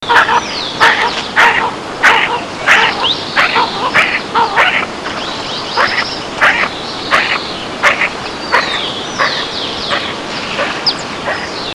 Anas cyanoptera cyanoptera - Pato colorado
patocolorado.wav